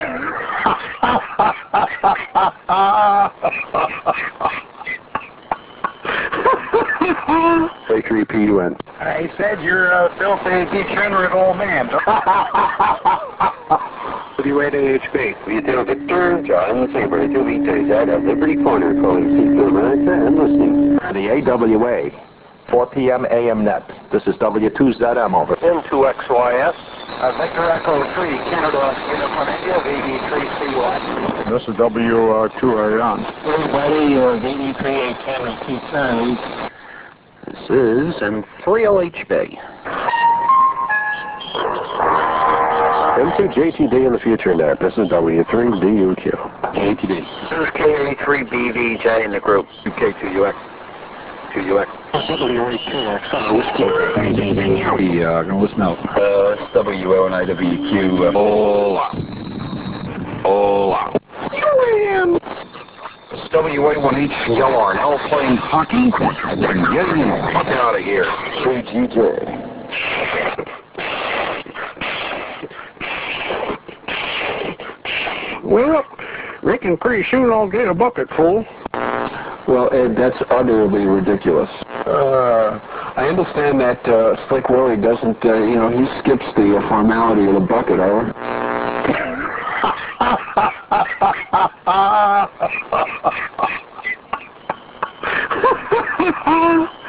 Northeast 75 Meters Sounds